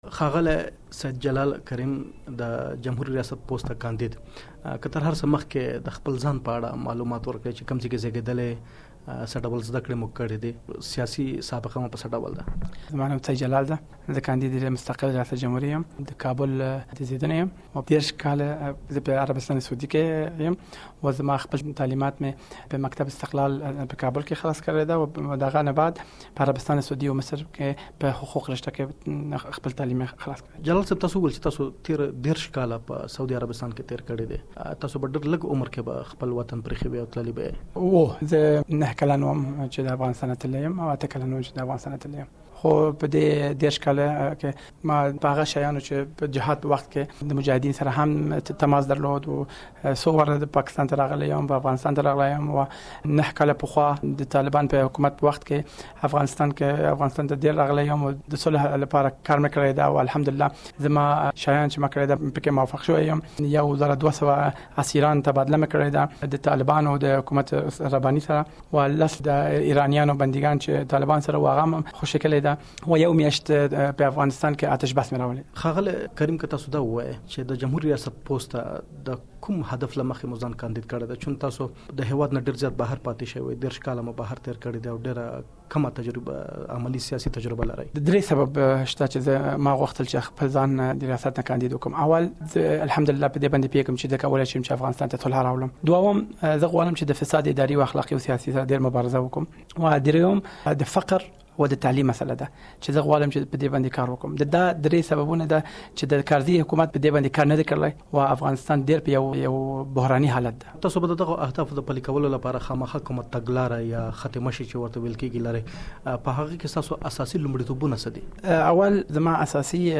ځانګړې مرکه واورﺉ